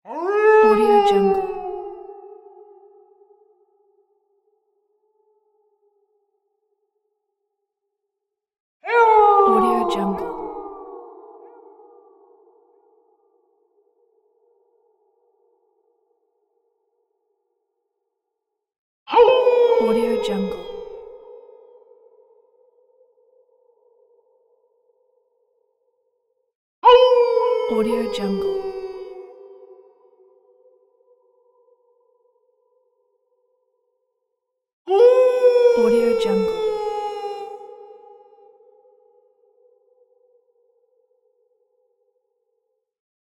Distant Wolf Howl Bouton sonore